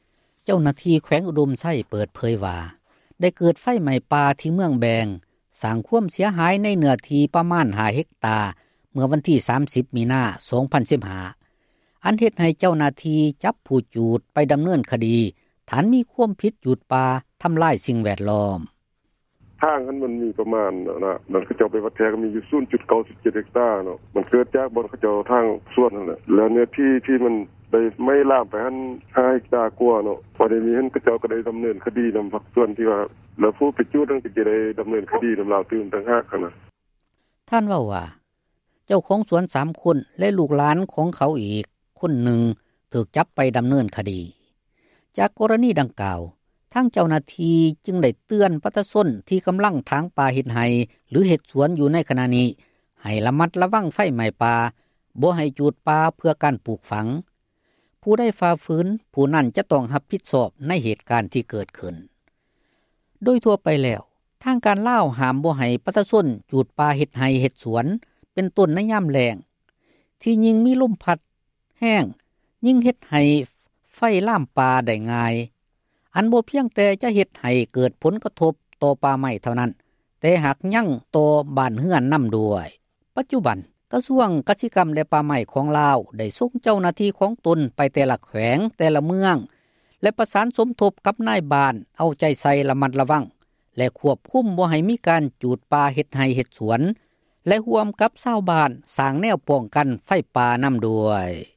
Citizen Journalist